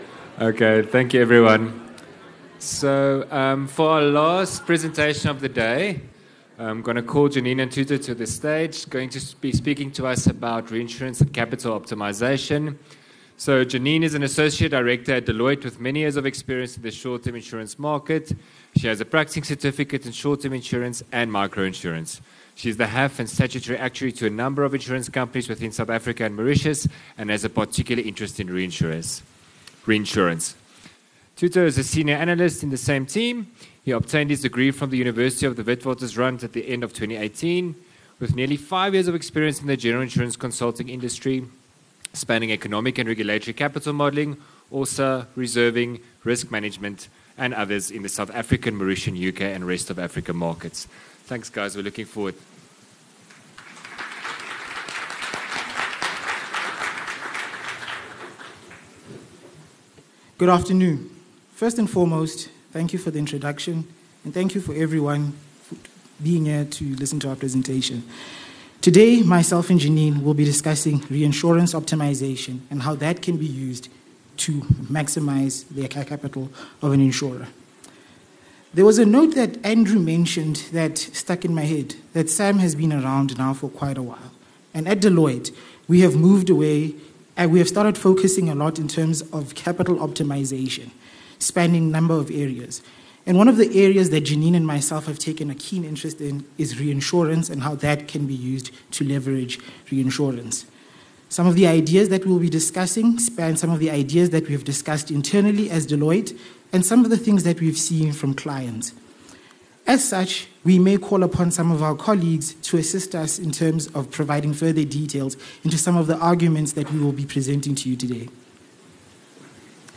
Short-Term Insurance Seminar 15 August 2024 The Venue Melrose Arch #8 The Power of Reinsurance